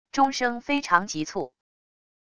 钟声非常急促wav音频